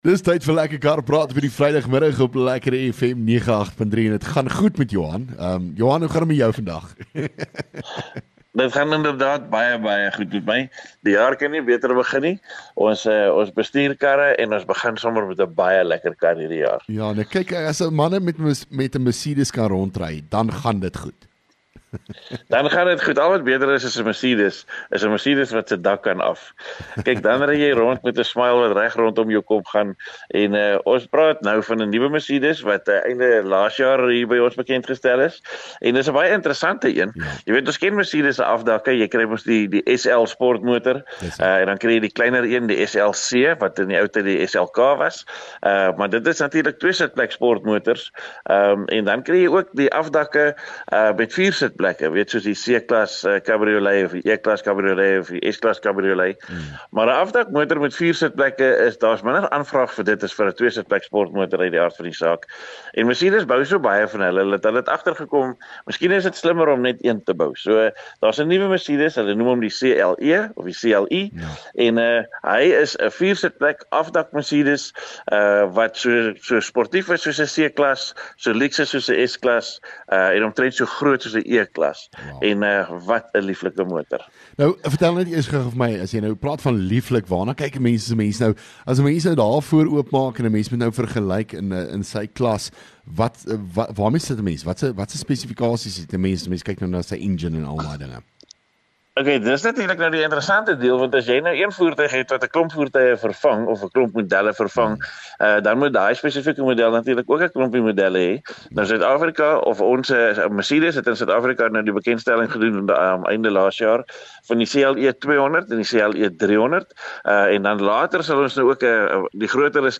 LEKKER FM | Onderhoude 17 Jan Lekker Kar Praat